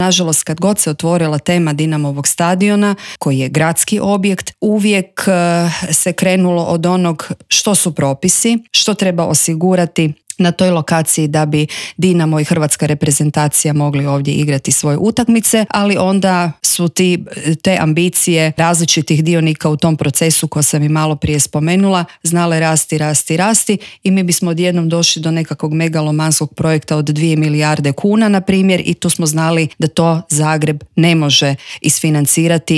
ZAGREB - Uoči lokalnih izbora u razgovoru za Media servis zamjenica gradonačelnika te kandidatkinja Bandić Milan 365 Stranke rada i solidarnosti za zagrebačku gradonačelnicu Jelena Pavičić Vukičević progovorila je o obnovi Zagreba od potresa kao i kakve planove ima s Dinamovim stadionom.